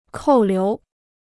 扣留 (kòu liú) พจนานุกรมจีนฟรี